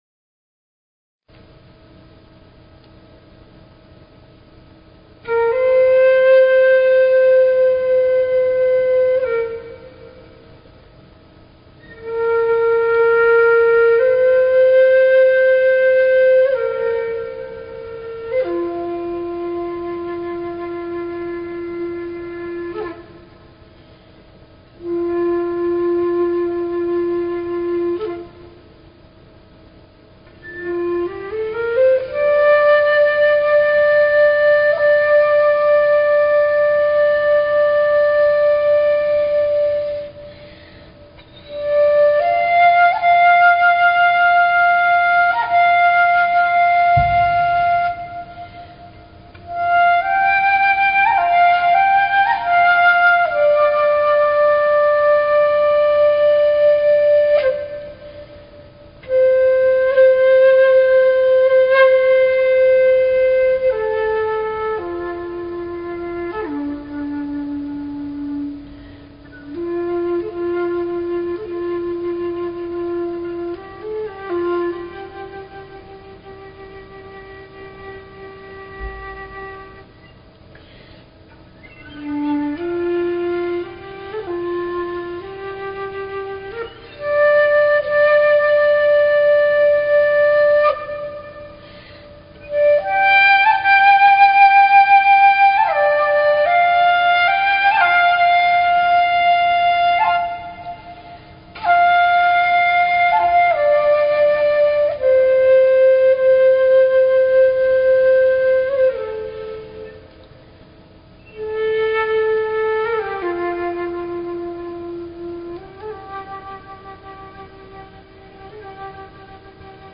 Talk Show Episode
The Economy and Other Changes Channeled information and a group discussion on preparation.